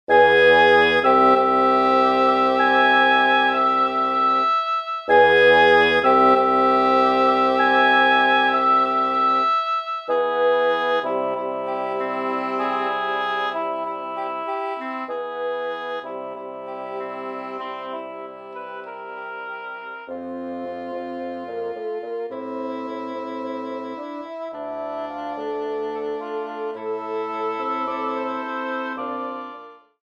Arreglo para quinteto de doble lengüeta
2 Oboes, 1 Corno Inglés, 2 Fagotes